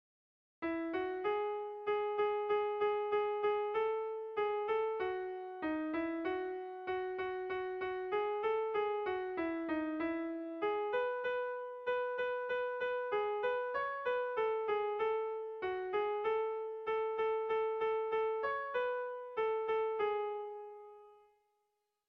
Doinu ederra.
Zortziko txikia (hg) / Lau puntuko txikia (ip)